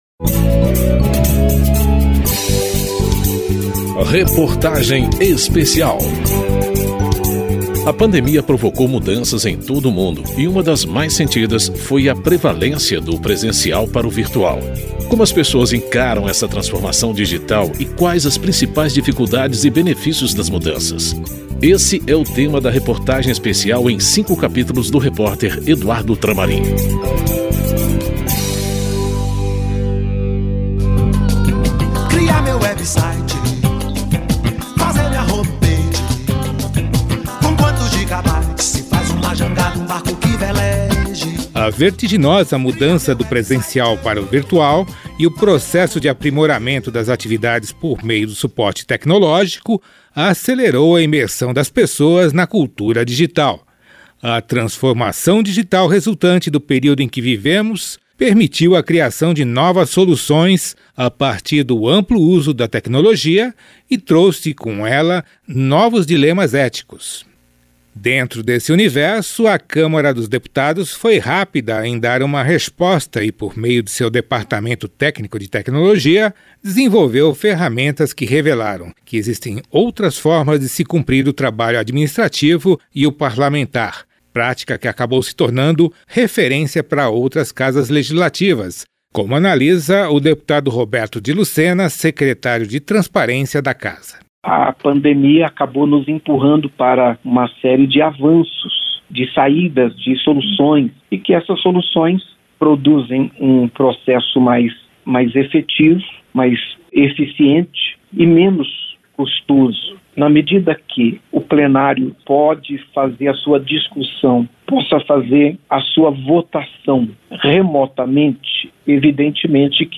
Reportagem Especial
Ouvidos nesse capítulo: deputado Roberto de Lucena (Pode-SP); deputada Joice Hasselmann (PSL-SP); e a deputada Tabata Amaral (PDT-SP)